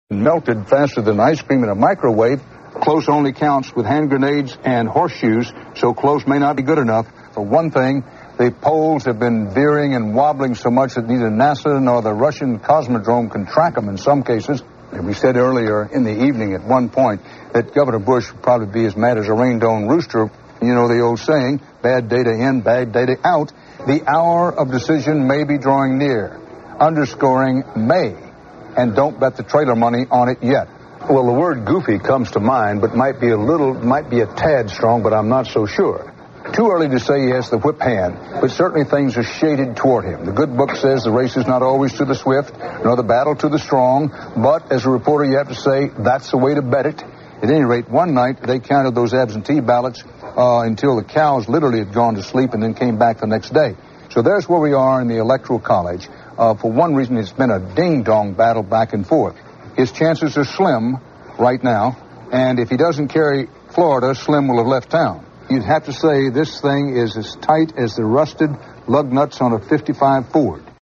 Dan Rather Collage
You may also hear the occasional cuss word in some translations: you have been warned.